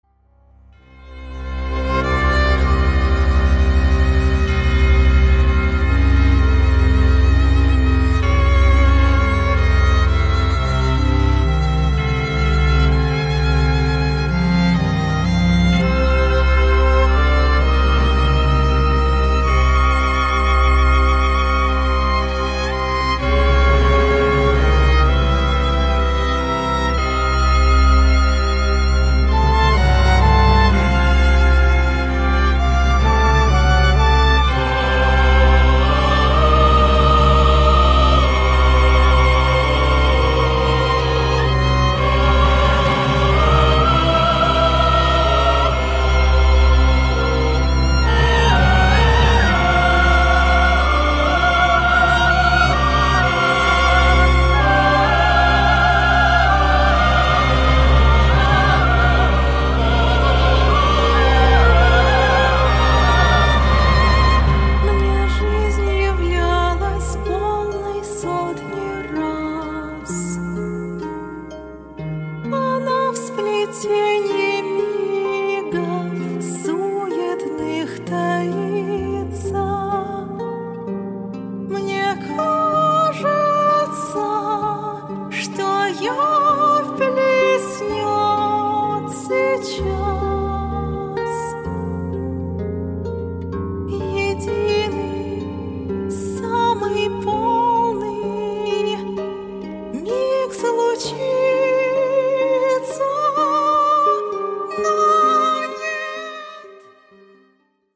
voice
violin
cello
harp